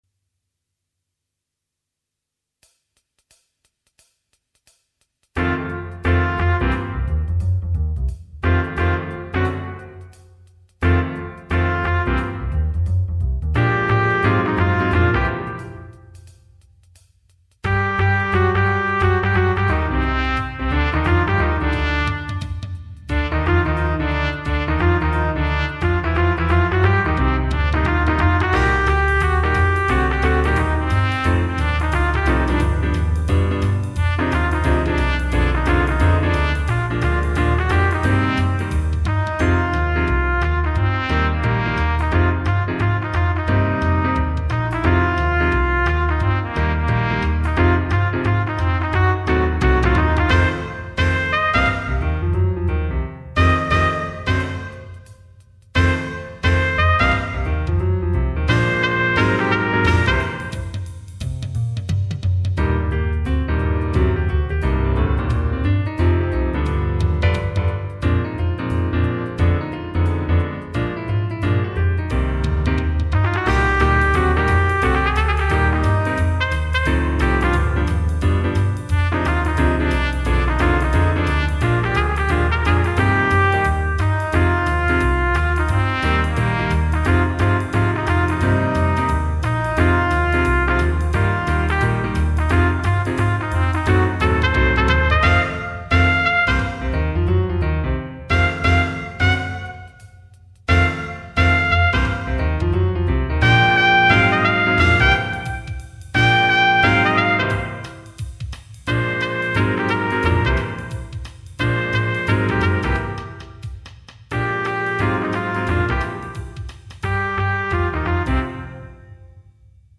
Instrumentation: C, Bb, Eb, Gtr, Keyboard, Bass, Drums
quartet arrangement